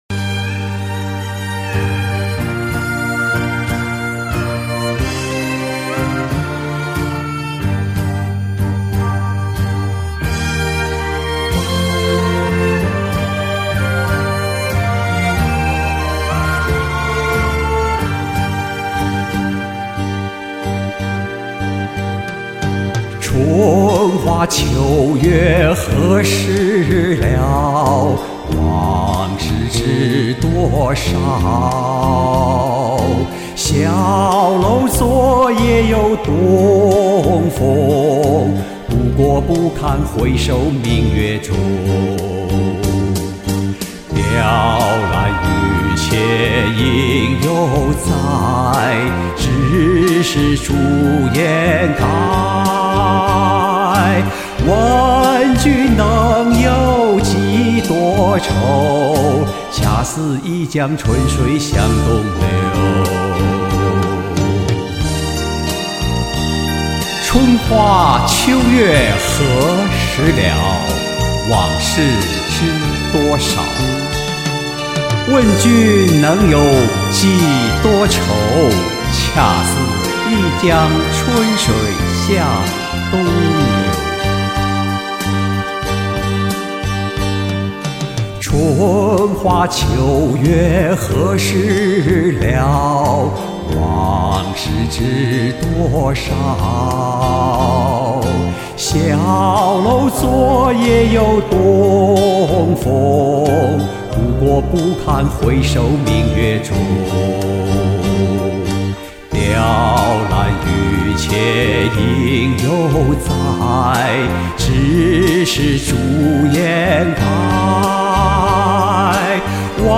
好嗓子！颂的部分声音更显年轻😊
深沉儒雅的嗓音，好听
第一次听这首歌的男声版，古典儒雅，道尽昔君王的惆怅感怀！
古风古韵的演唱，很好听！
动情，感人，流畅的好演绎。